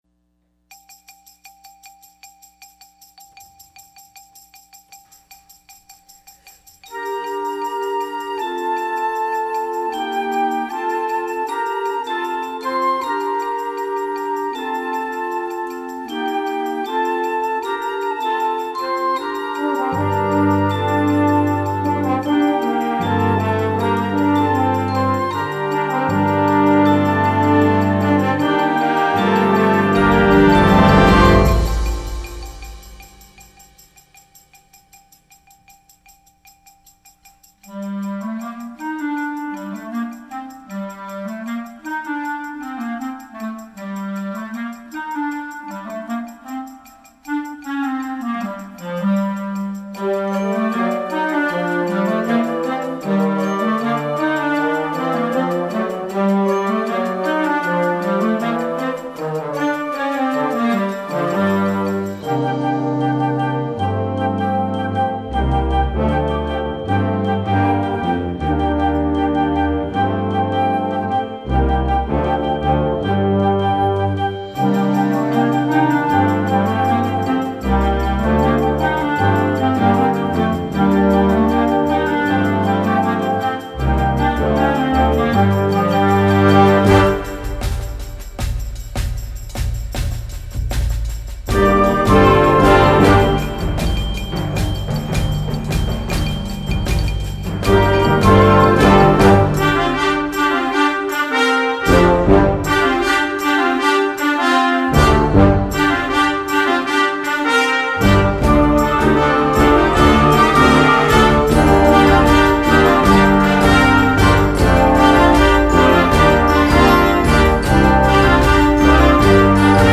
Gattung: A G Minor Groove for Concert Band
Besetzung: Blasorchester